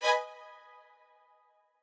strings11_19.ogg